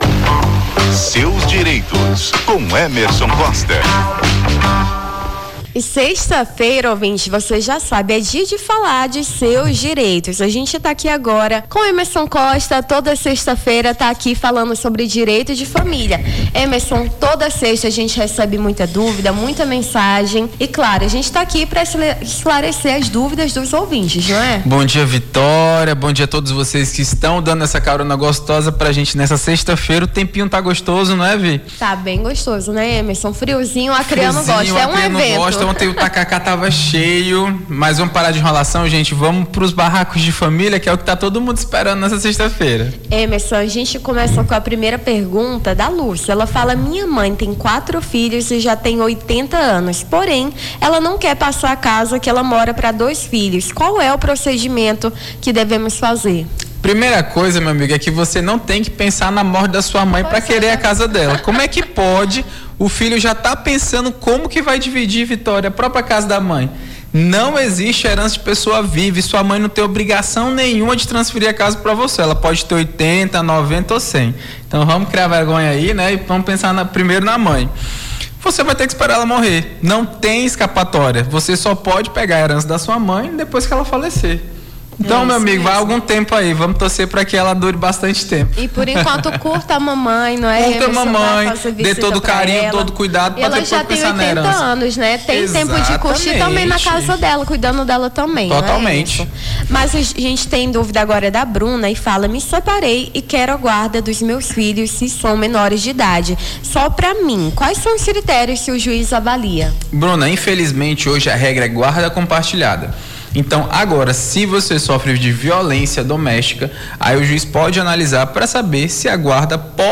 Seus Direitos: advogado tira dúvidas sobre direitos de família